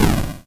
ihob/Assets/Extensions/explosionsoundslite/sounds/bakuhatu64.wav at master
bakuhatu64.wav